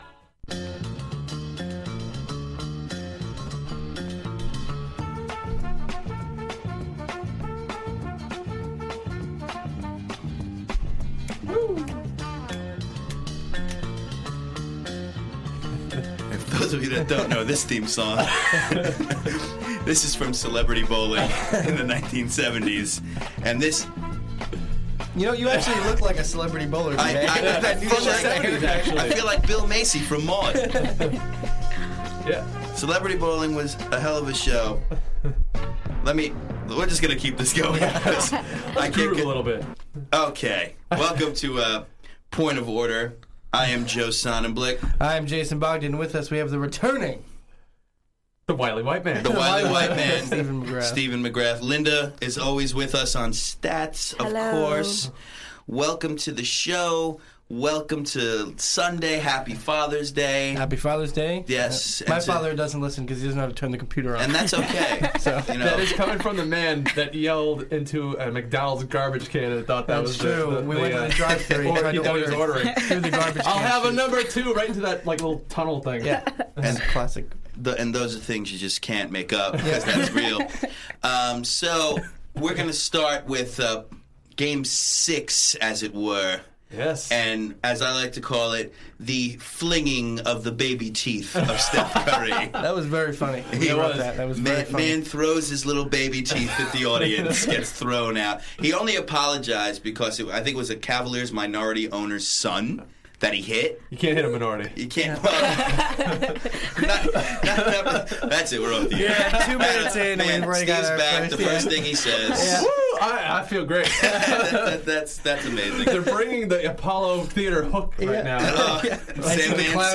Our recap of game six of the NBA finals, the fellers and lady predict game 7, breakdown the keys to victory, the need to do's of each team.